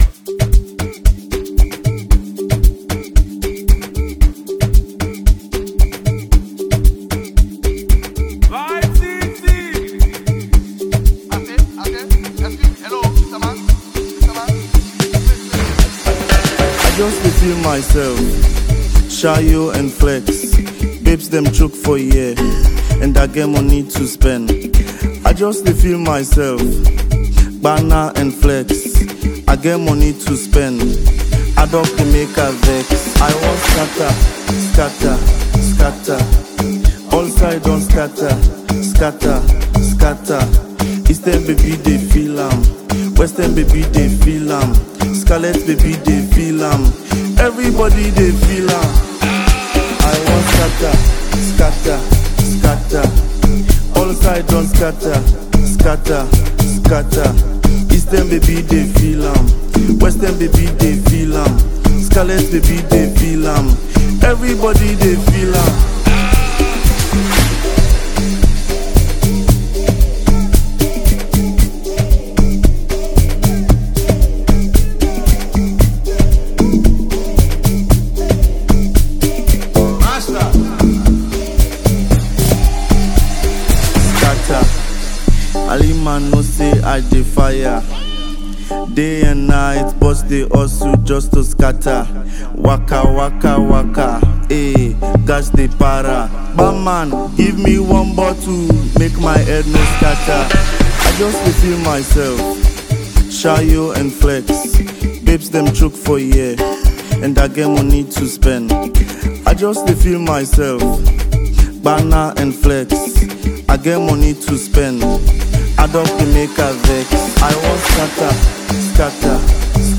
amapiano banger